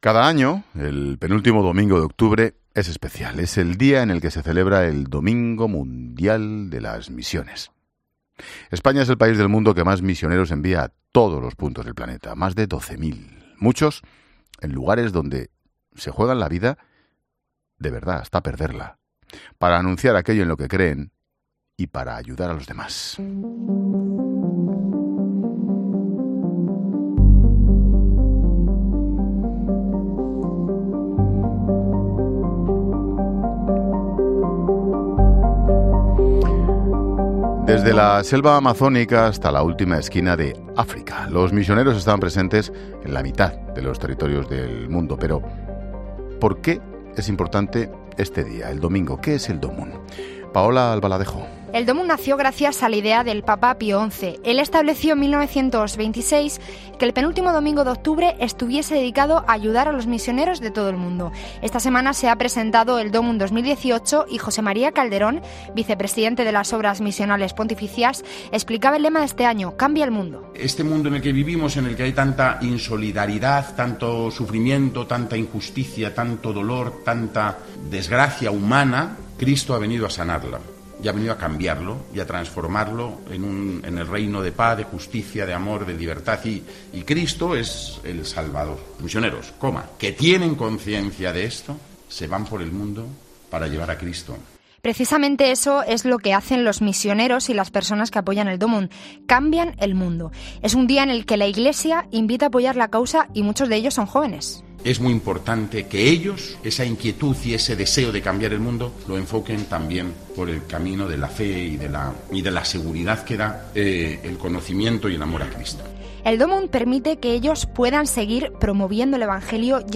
Misioneros en estos países han pasado por los micrófonos de La Linterna.